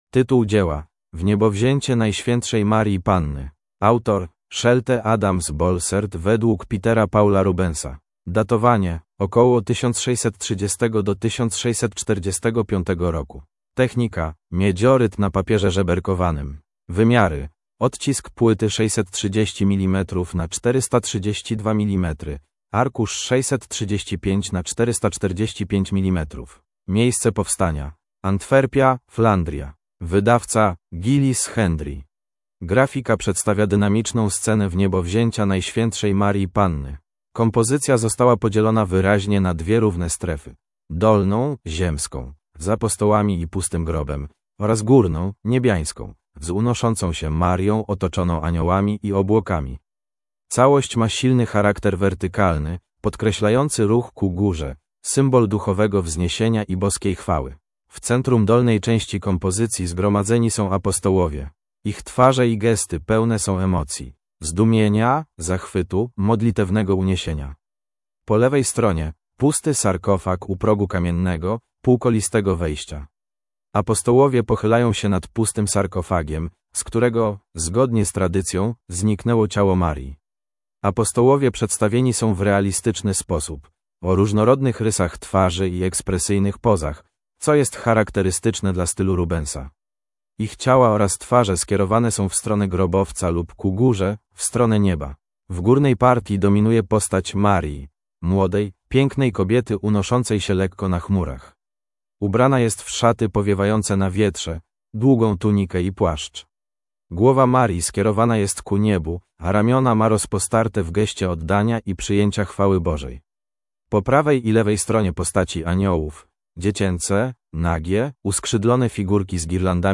MNWr_audiodeskr_Wniebowziecie_Najswietszej_Marii_Panny.mp3